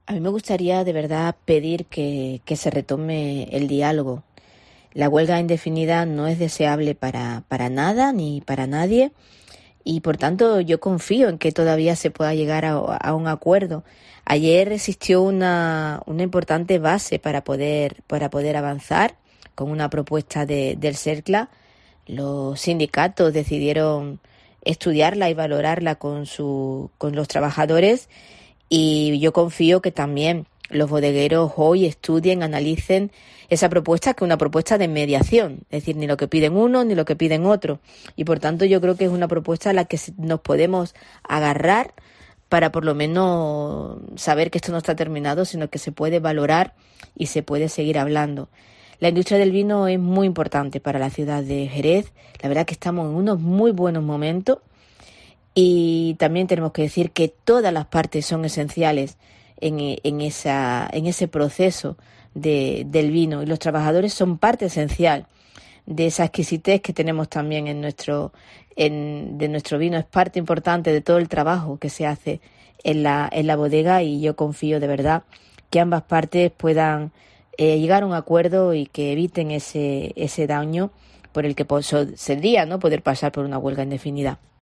Así llama la alcaldesa de Jerez a trabajadores y bodegueros para que eviten la huelga indefinida